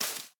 Minecraft Version Minecraft Version 1.21.5 Latest Release | Latest Snapshot 1.21.5 / assets / minecraft / sounds / block / cherry_leaves / break1.ogg Compare With Compare With Latest Release | Latest Snapshot
break1.ogg